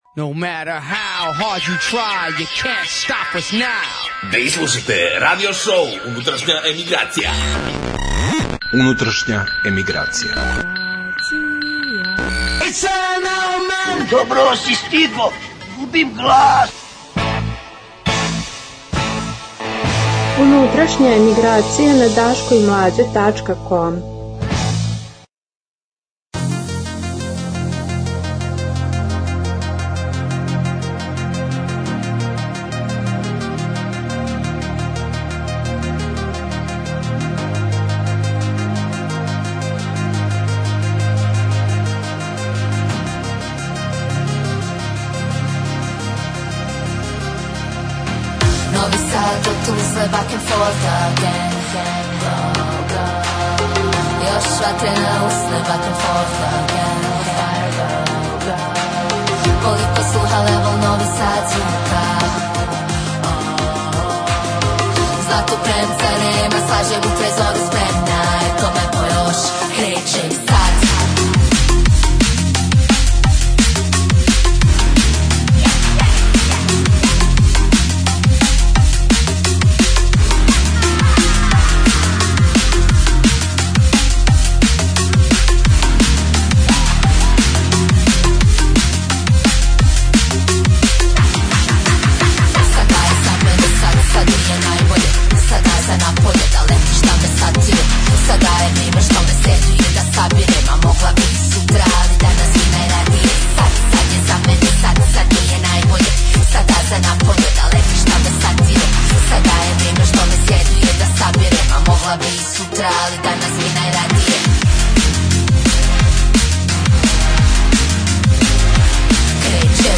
Dobra muzika non-stop!